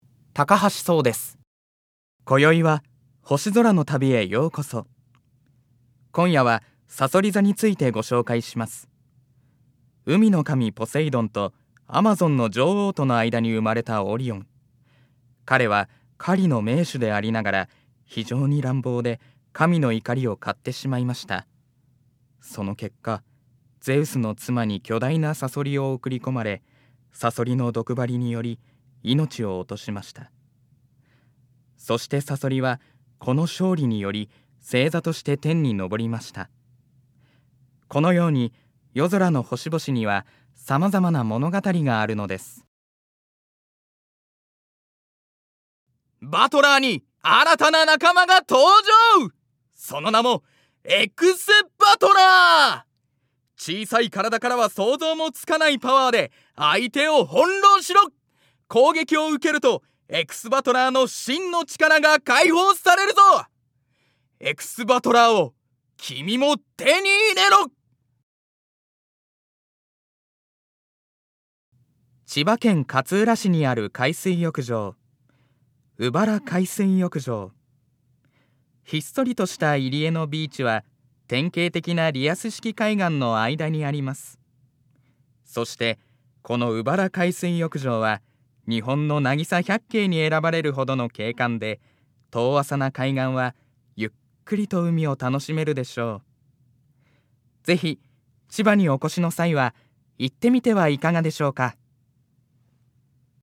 ◆ナレーション